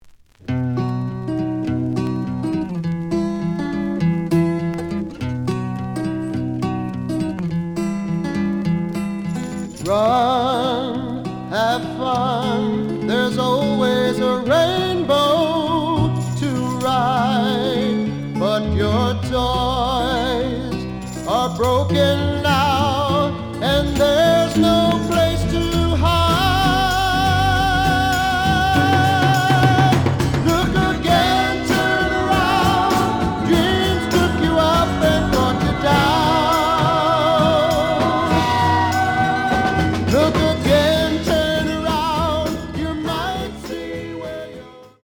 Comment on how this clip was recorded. The audio sample is recorded from the actual item. Some damage on both side labels. Plays good.)